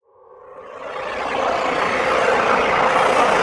Percs
shawty perc.wav